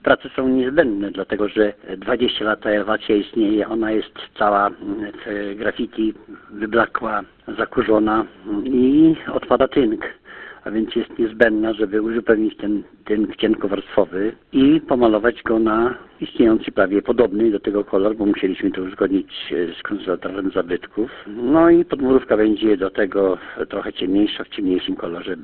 – Stara elewacja była w opłakanym stanie – przyznaje Zygmunt Kruszyński, starosta grajewski.